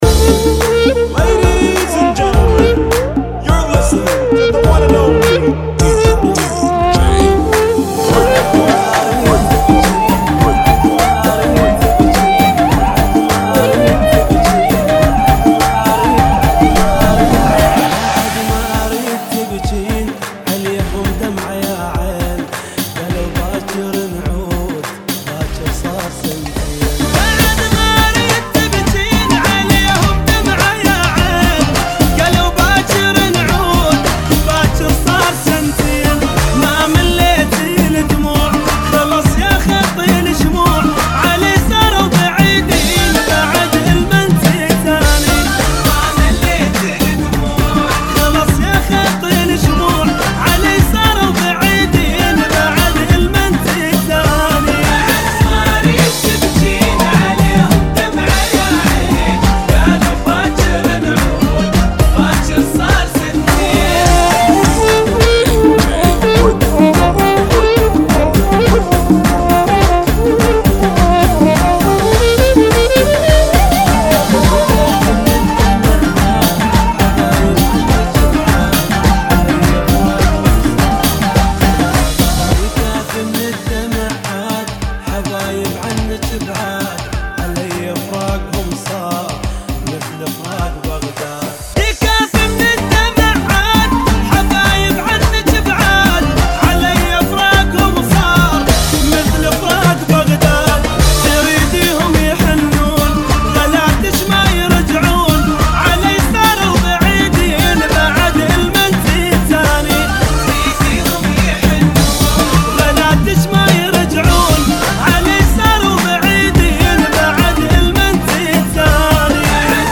104 Bpm
beat